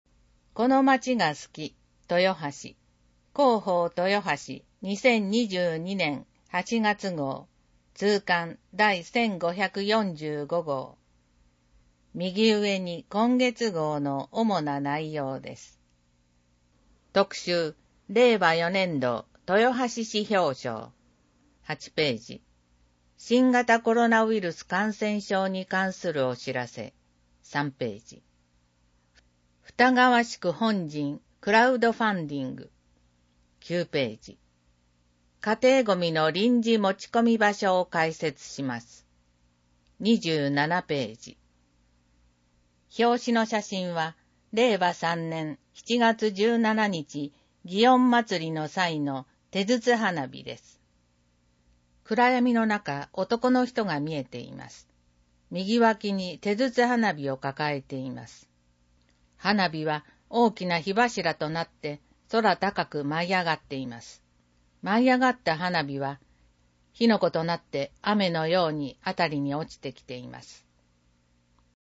• 「広報とよはし」から一部の記事を音声でご案内しています。視覚障害者向けに一部読み替えています。